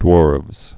(dwôrvz)